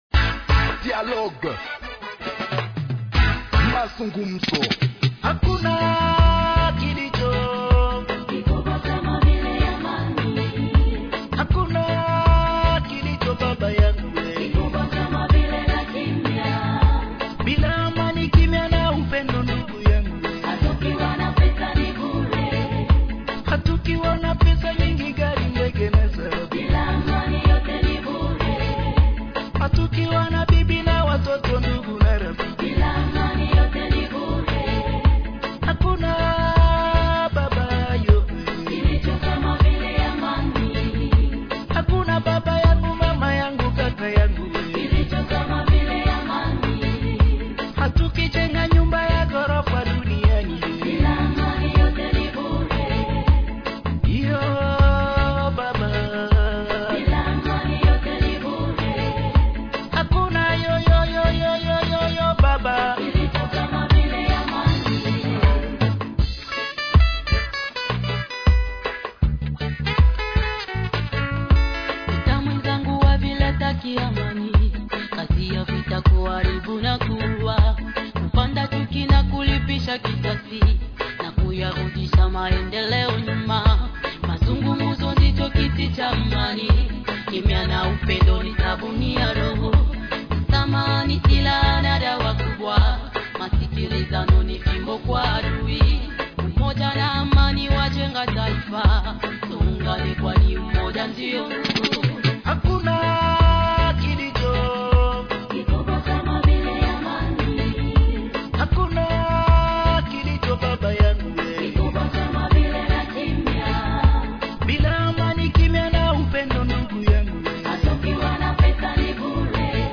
Journal en Français du 21 Mai 2025 – Radio Maendeleo